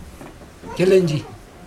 [kilind͡ʑi/gilindi] noun duck